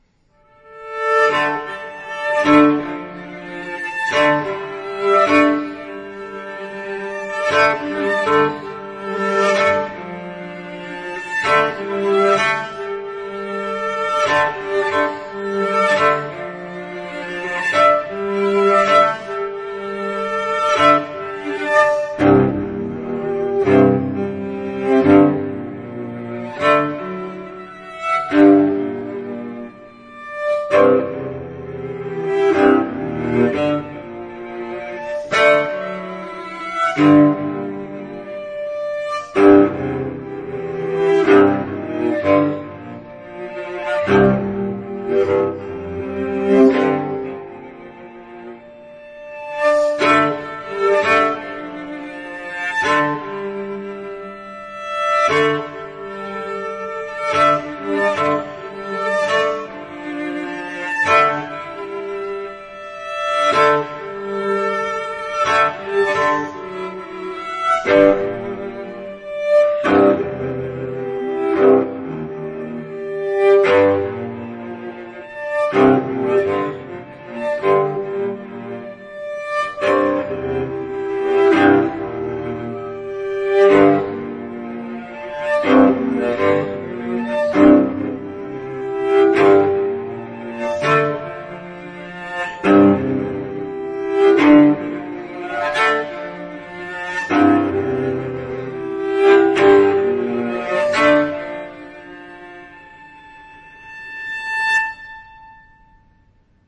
這張現代大提琴作品，就以音樂家為標題，
所以在這張現場錄音的專輯裡，
其中一部是琴獨奏，另外則是與管弦樂團的合作。
這些作品，試圖脫離大提琴的傳統表現方式與音色。